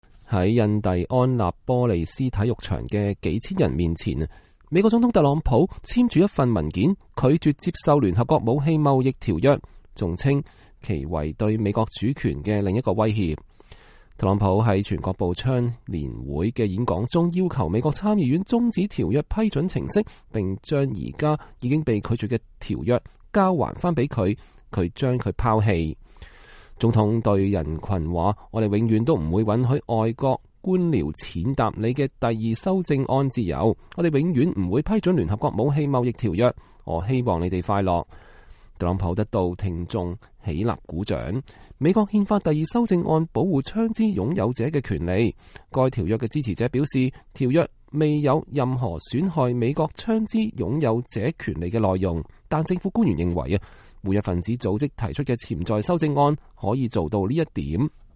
在印第安納波利斯體育場的數千人面前，美國總統特朗普簽署了一份文件，拒絕接受“聯合國武器貿易條約”，還稱其為對美國主權的另一個威脅。
特朗普得到了聽眾起立鼓掌。